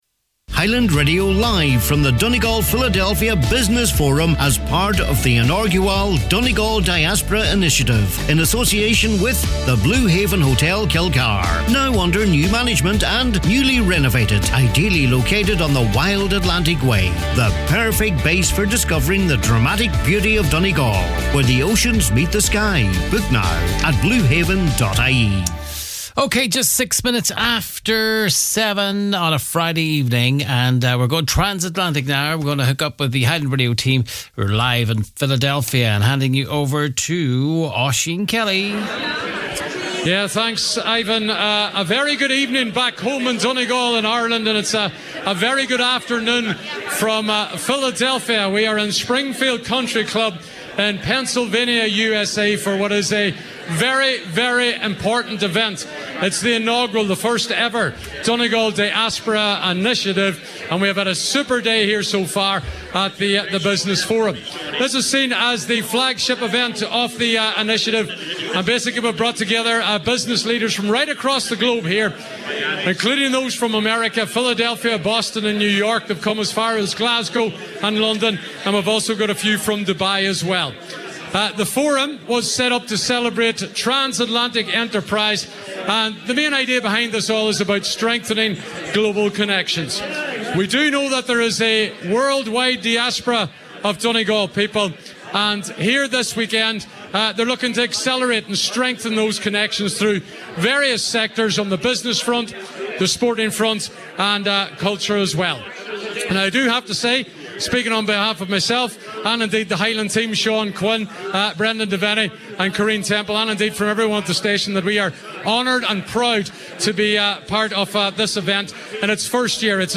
Highland Radio broadcast live from Philadelphia at the inaugural Donegal Diaspora Initiative’s Business Forum this evening.